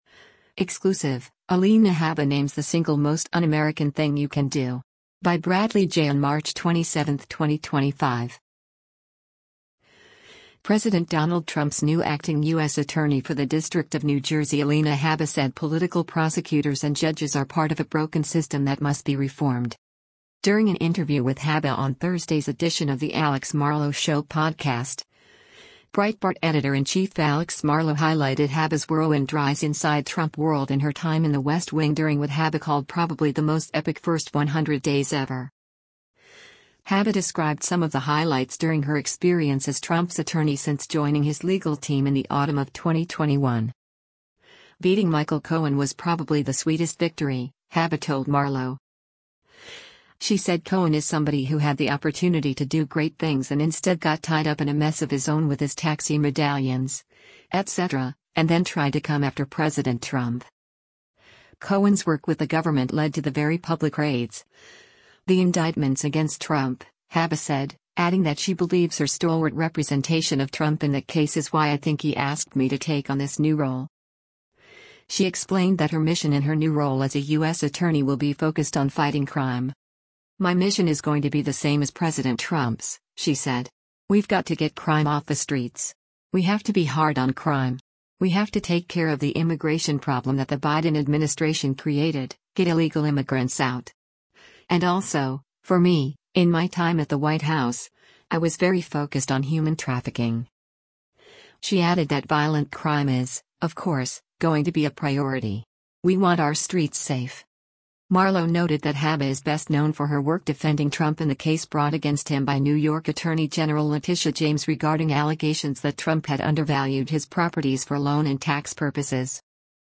During an interview with Habba on Thursday’s edition of The Alex Marlow Show podcast, Breitbart Editor-in-Chief Alex Marlow highlighted Habba’s whirlwind rise inside Trump world and her time in the West Wing during what Habba called “probably the most epic first 100 days ever.”